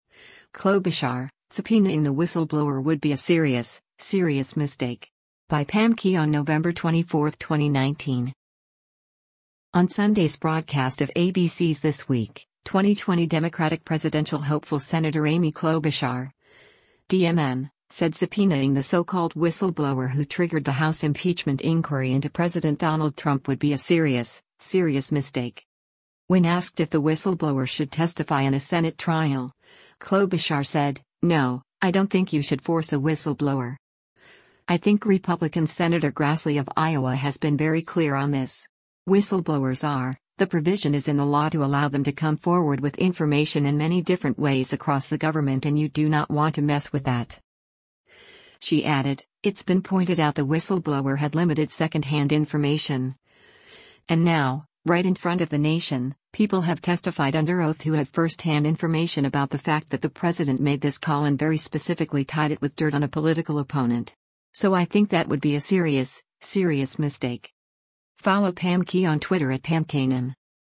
On Sunday’s broadcast of ABC’s “This Week,” 2020 Democratic presidential hopeful Sen. Amy Klobuchar (D-MN) said subpoenaing the so-called whistleblower who triggered the House impeachment inquiry into President Donald Trump “would be a serious, serious mistake.”